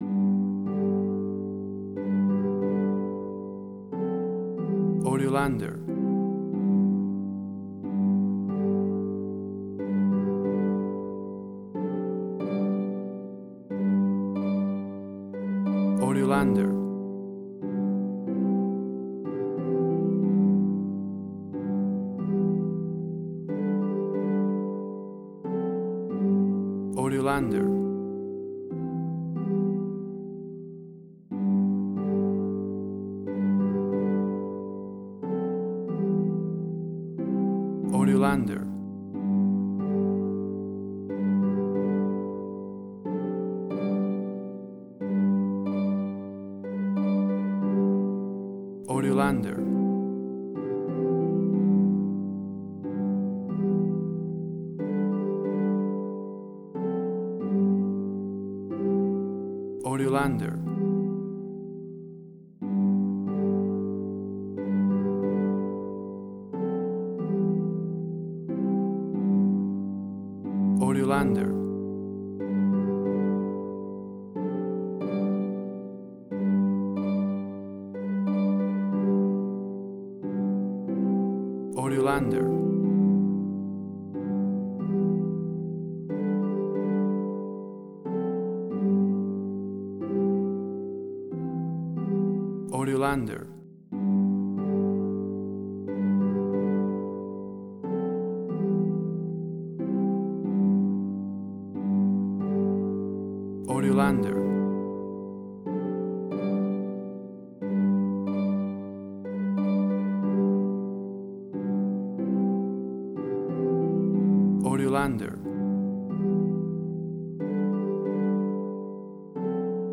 A traditional church organ version of this classic hymn.
Tempo (BPM): 90